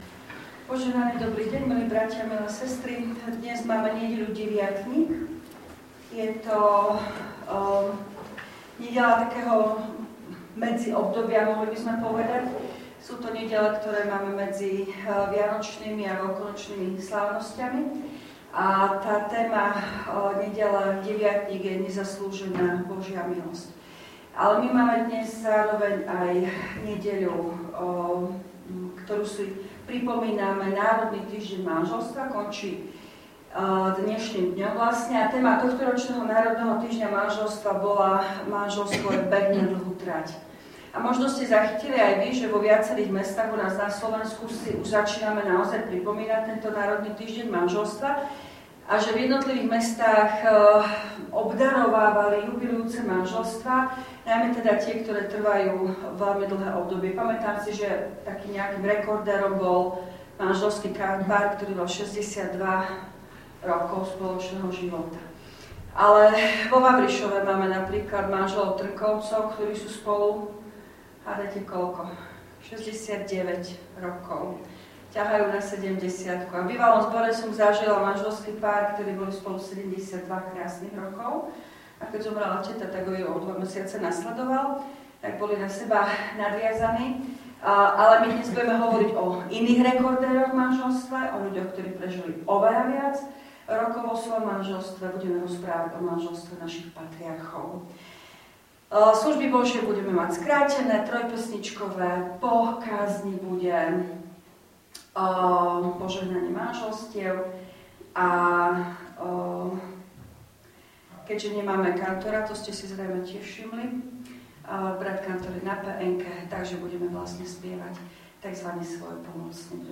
V nasledovnom článku si môžete vypočuť zvukový záznam zo služieb Božích – Nedeľa Deviatnik.